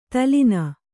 ♪ talina